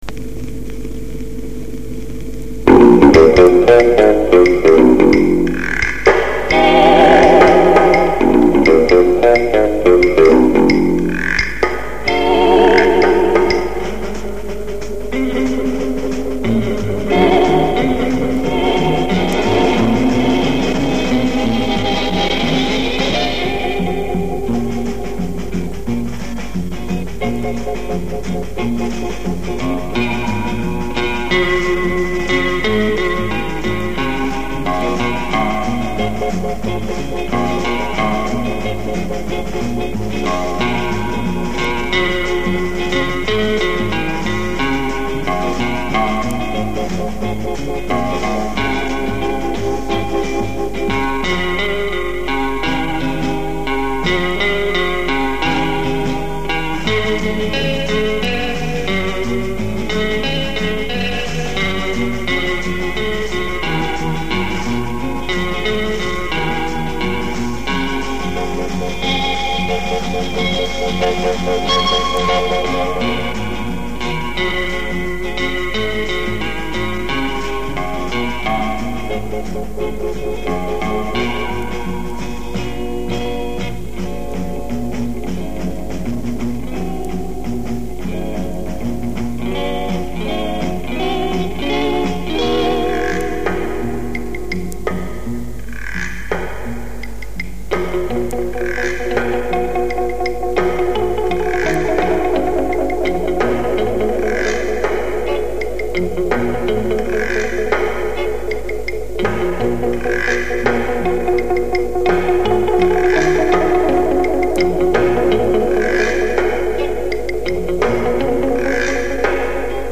Хранилась на ленте на балконе много лет, потому и качество низкое.